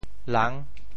“朗”字用潮州话怎么说？